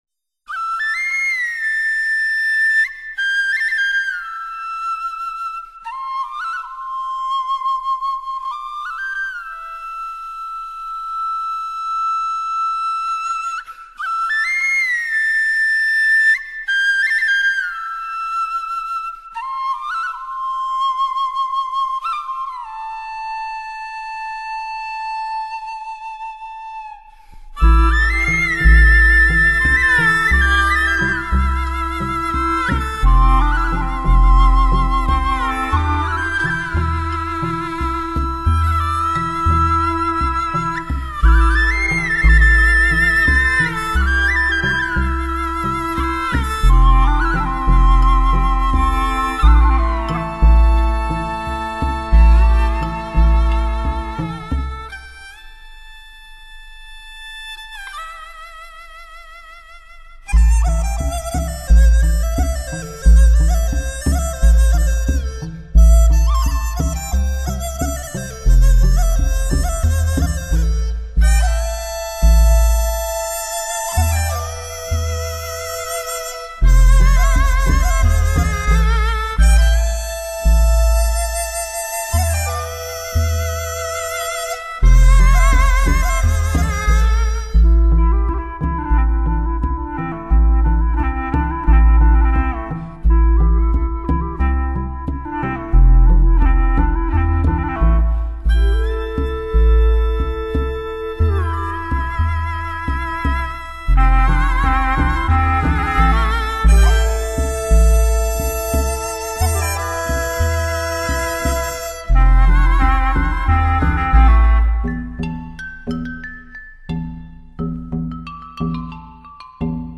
以三十多种竹制乐器演奏出12首亚洲各地名曲的竹乐大典
唱片以竹制乐器演绎源自中国、印尼、日本、朝鲜、缅甸5个国家的12首传统民谣，
充满异域色彩和独特的韵味。
为了追求音效的自然逼真，唱片特别挑选在中央电视台480平方米录音棚，